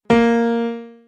Piano Keys C Scale New